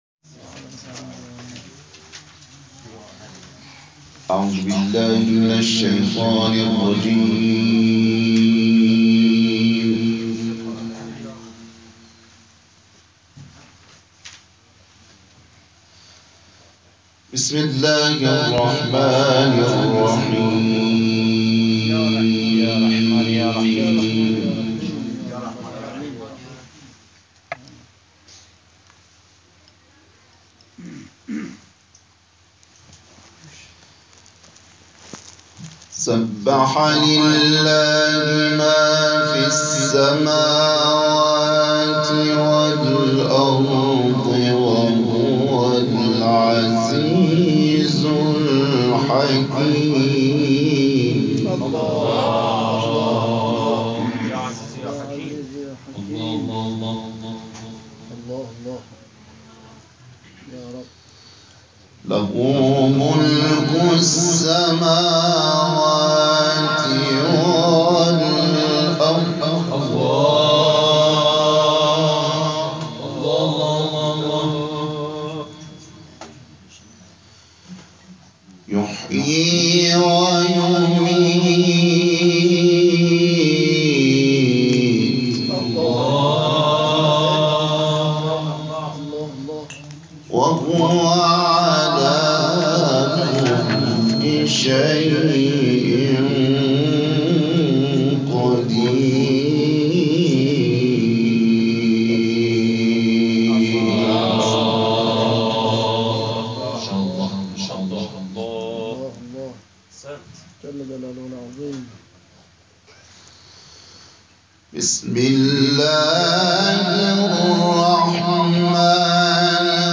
تلاوت سوره مبارکه «حدید»